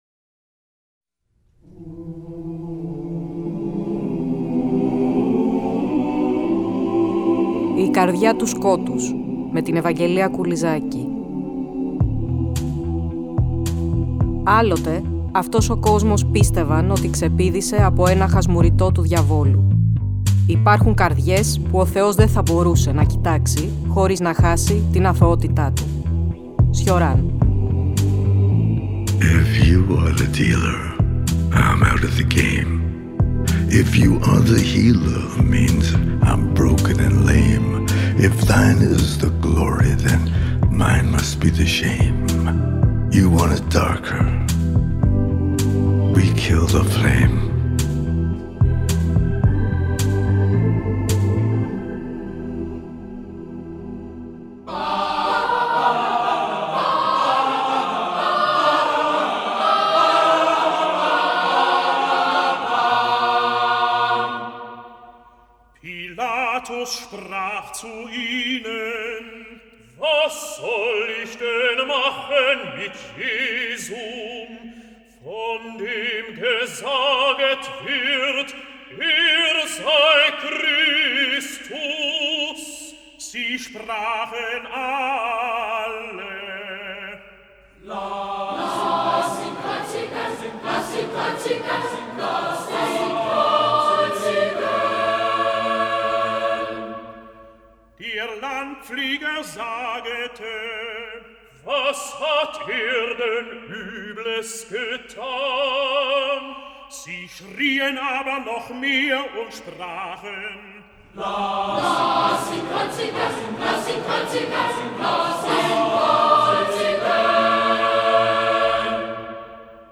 Στην εκπομπή αναζητούμε -και ακολουθούμε- τα ίχνη του Βαραββά, με την αντίστοιχη μουσική επένδυση.